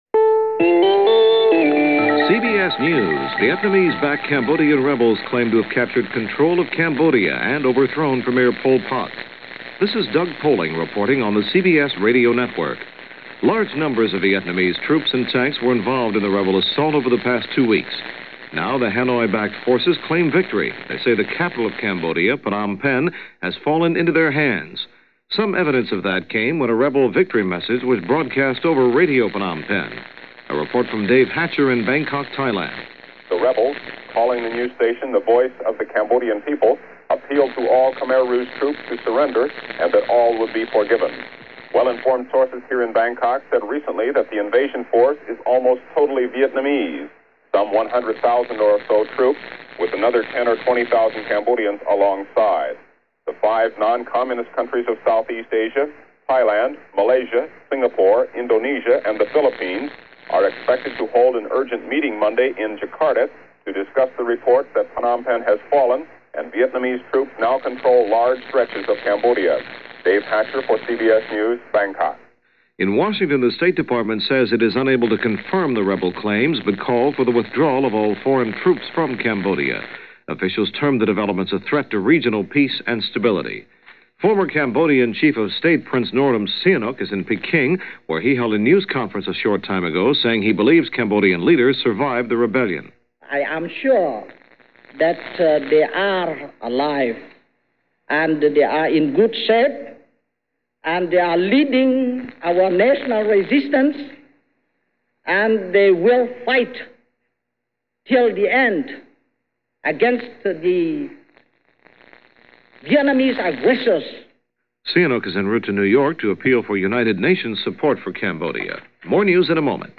CBS Radio Hourly News